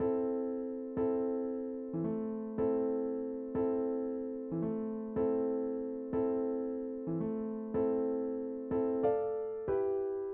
老吓人的钢琴
标签： 93 bpm Hip Hop Loops Piano Loops 1.74 MB wav Key : Unknown
声道立体声